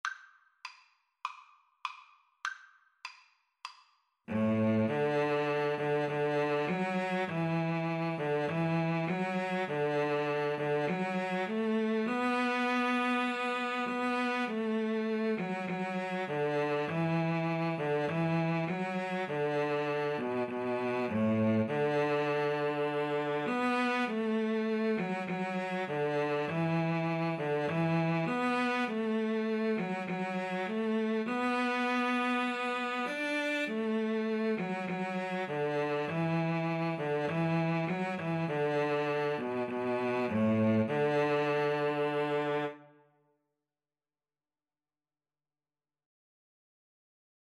Free Sheet music for Cello Duet
D major (Sounding Pitch) (View more D major Music for Cello Duet )
4/4 (View more 4/4 Music)